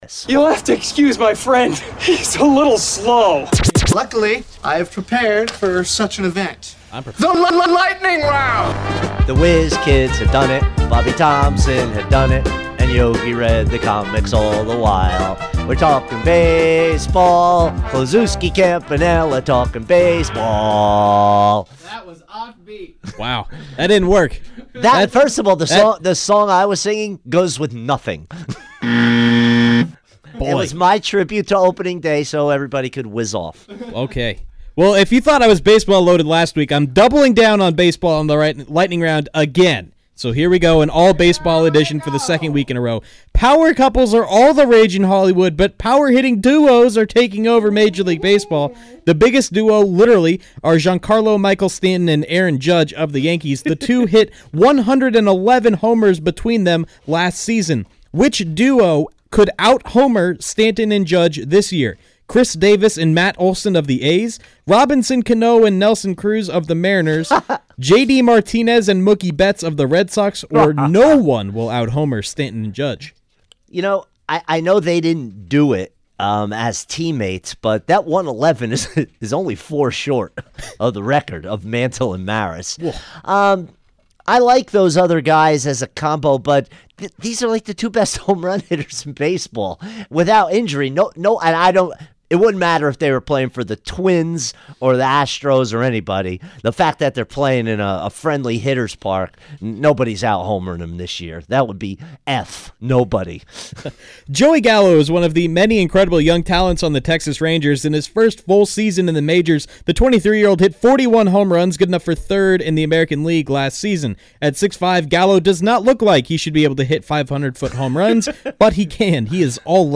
fires through the days biggest sports stories rapid-fire style